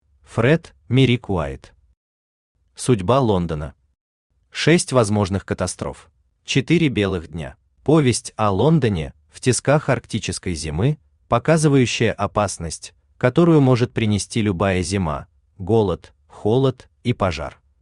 Аудиокнига Судьба Лондона.